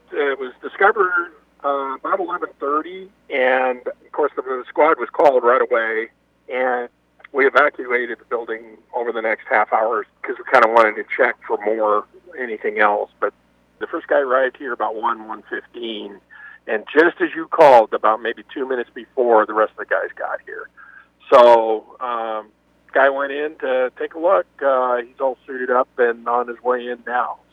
We spoke with Undersheriff Ron Cameron a few minutes before 2:00, just as the Bomb Squad had arrived.